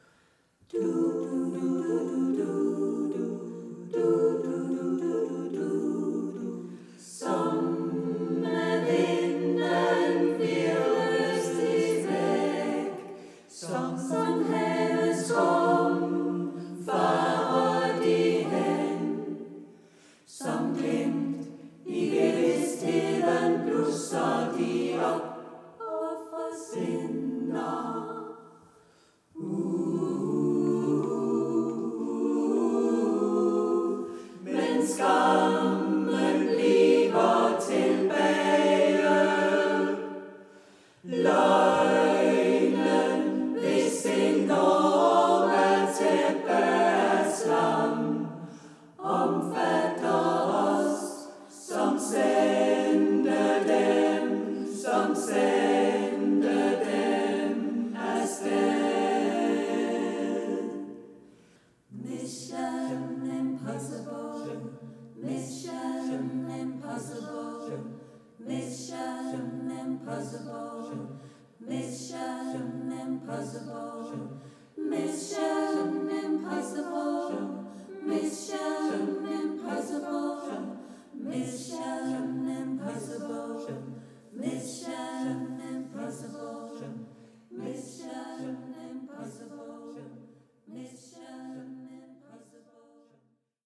Klang ud over det sædvanlige, leg med skæve rytmer
og virtuos sang, fra den dybeste alt-klang
til den højeste  soprantone –